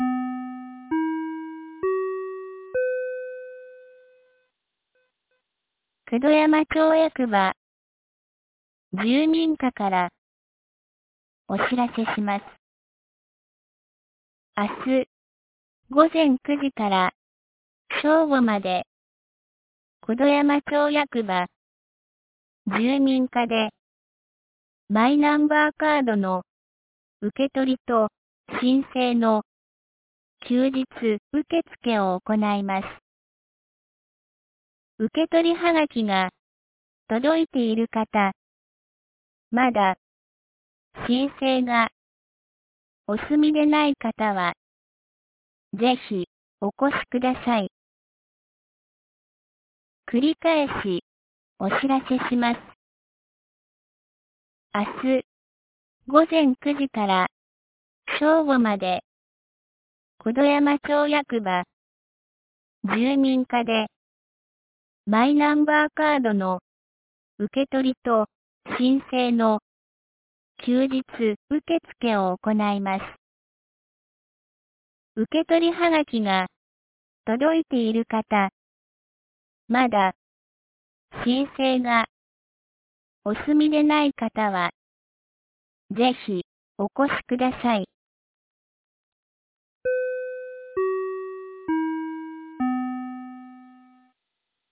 2024年11月29日 15時01分に、九度山町より全地区へ放送がありました。